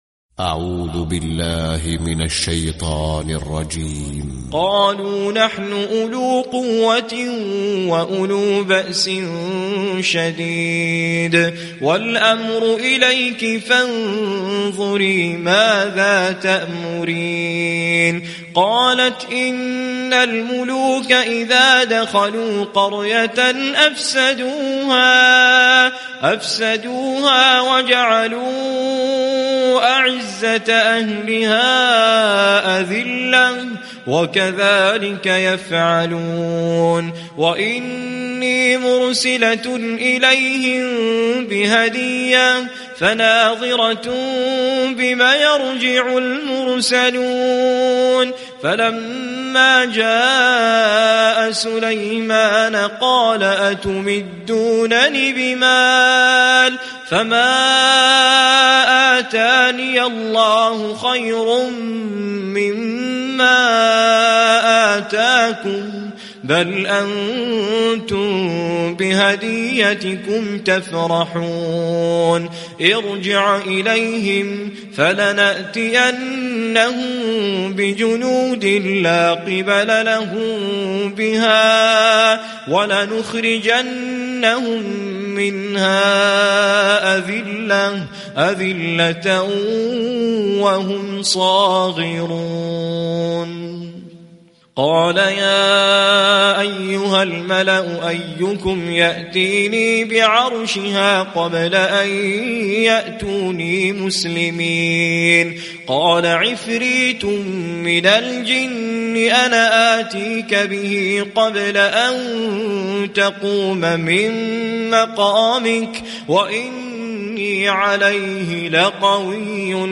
🌾💛•تلاوة مميزة•💛🌾